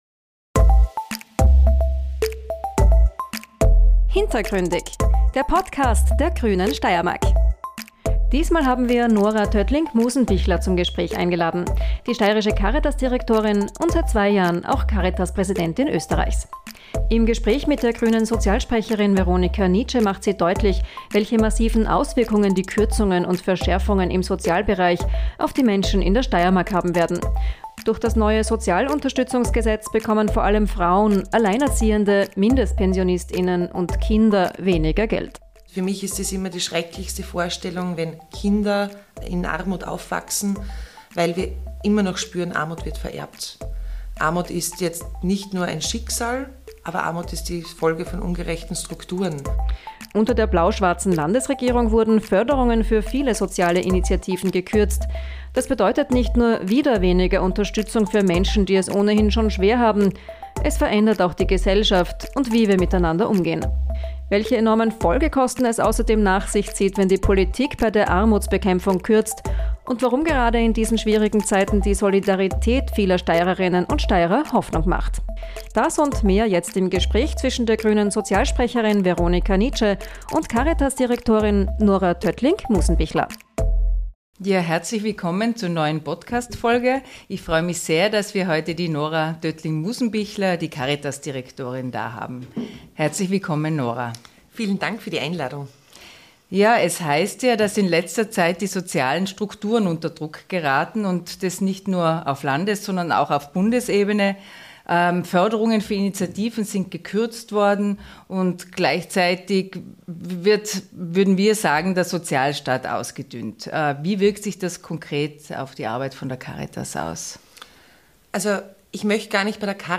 im Gespräch mit der Grünen Sozialsprecherin Veronika Nitsche